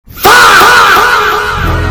ding dong
ding dong music